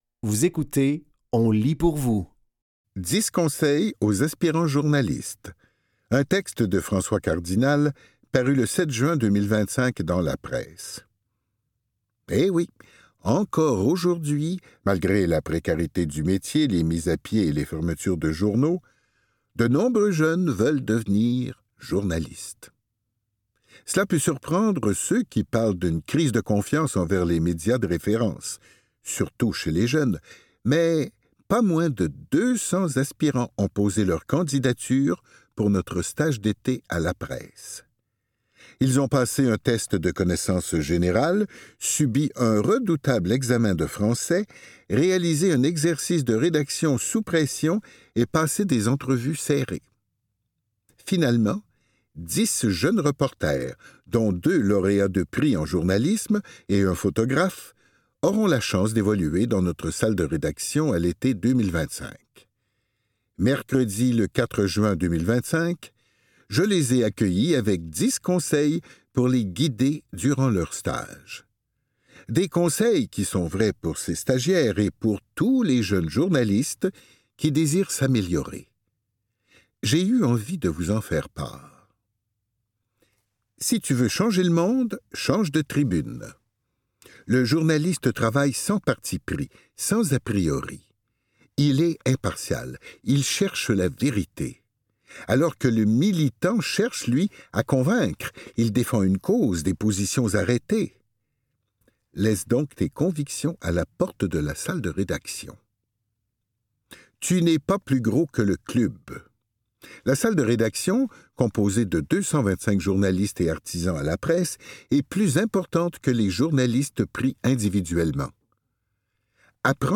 Dans cet épisode de On lit pour vous, nous vous offrons une sélection de textes tirés des médias suivants : La Presse, Le Devoir et La Revue du CREMIS. Au programme: 10 conseils aux aspirants journalistes, un texte de François Cardinal, paru le 07 juin 2025 dans La Presse.